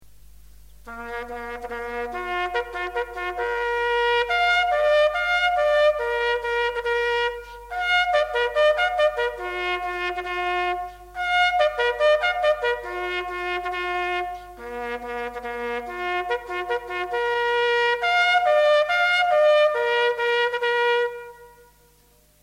Warning for Parade
Warning-for-Parade.mp3